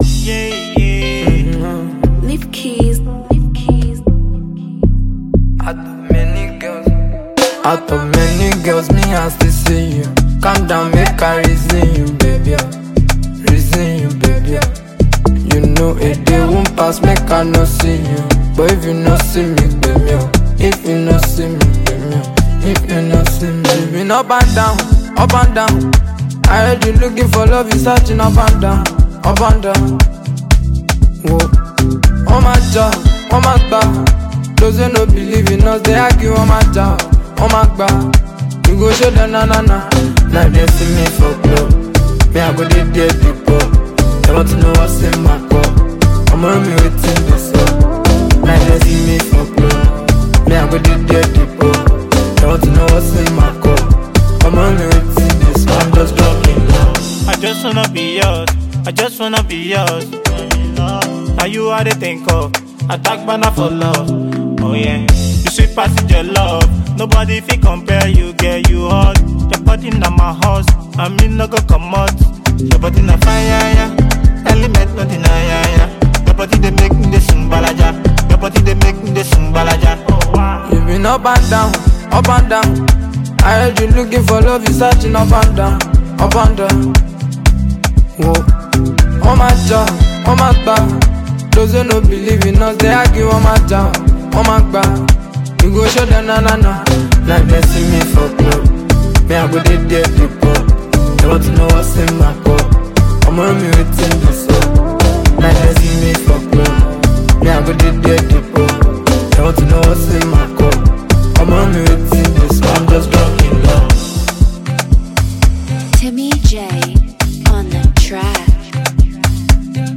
melodic presentation
vocalist